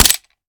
weap_mike9a3_fire_last_plr_mech_03.ogg